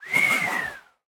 Minecraft Version Minecraft Version latest Latest Release | Latest Snapshot latest / assets / minecraft / sounds / mob / breeze / idle_air1.ogg Compare With Compare With Latest Release | Latest Snapshot
idle_air1.ogg